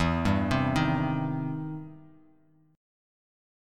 EmM7#5 chord